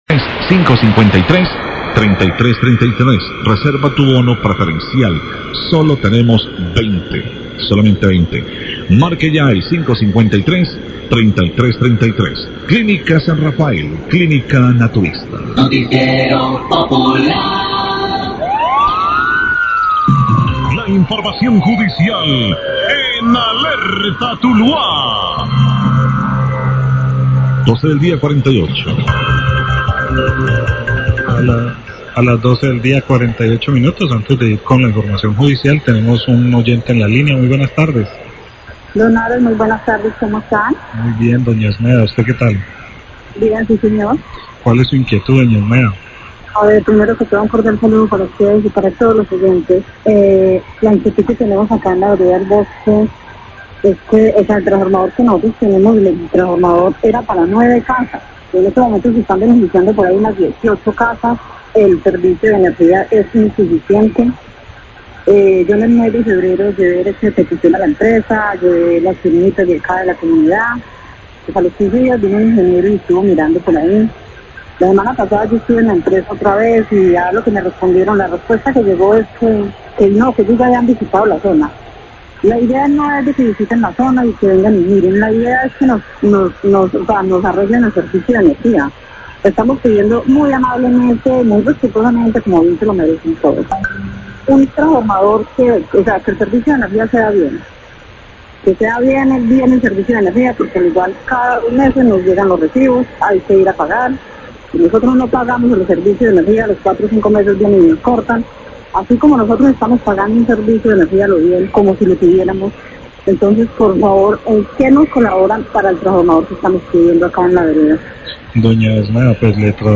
Radio
queja oyente
Llamada de oyente de vereda el bosque zona rural de Tuluá se queja por la insuficiencia del servicio de energía en el sector porque el transformador que hay en la zona tenía capacidad para nueve casas pero en este momento se deben estar beneficiando más de 18 familias